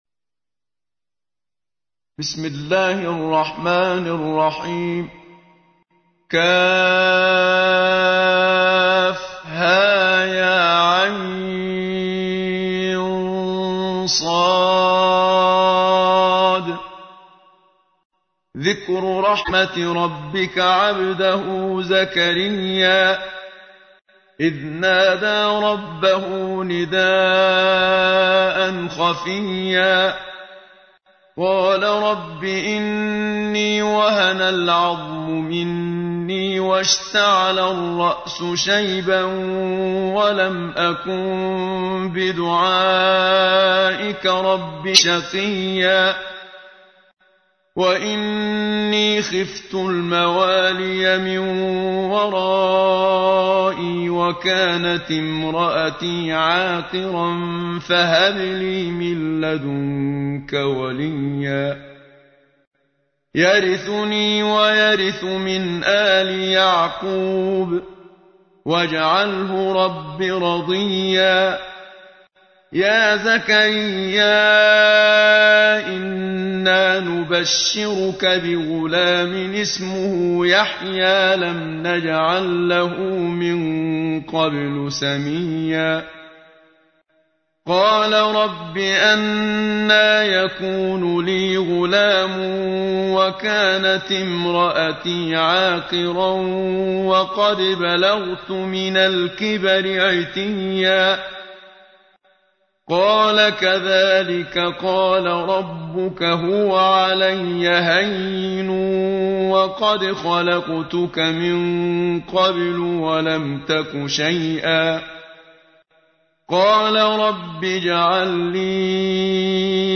تحميل : 19. سورة مريم / القارئ محمد صديق المنشاوي / القرآن الكريم / موقع يا حسين